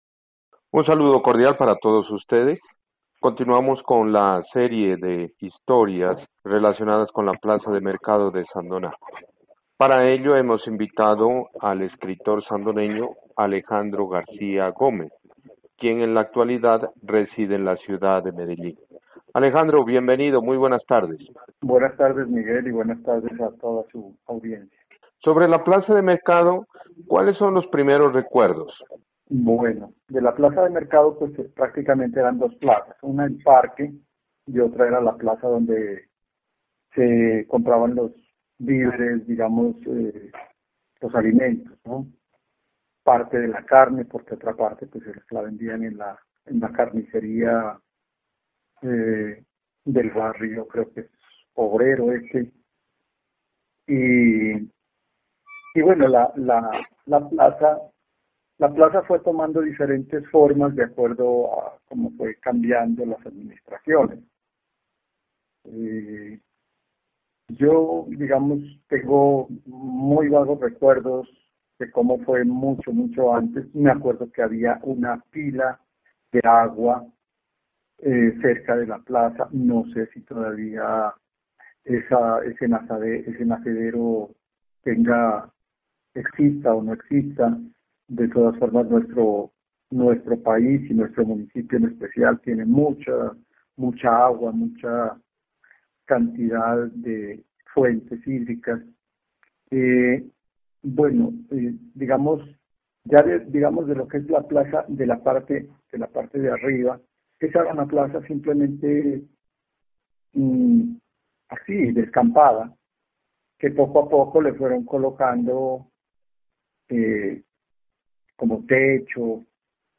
Esta es la conversación